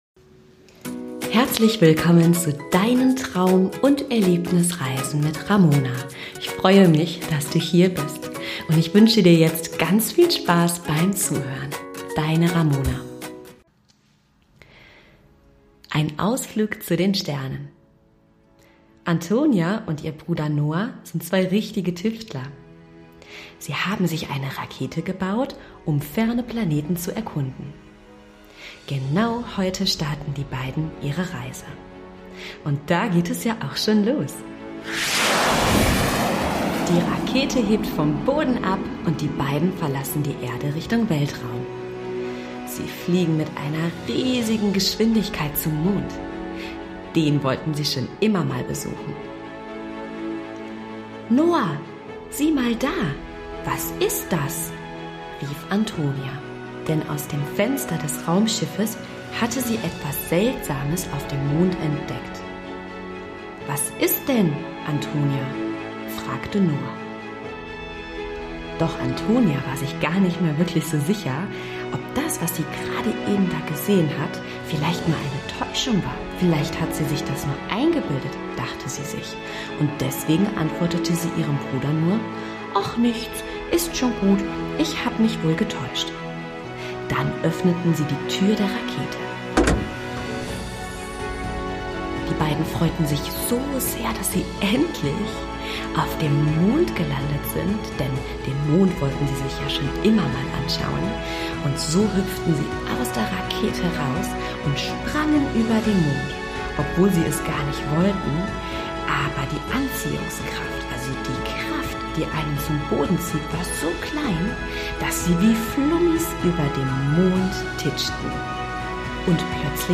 Traumreise für Kinder.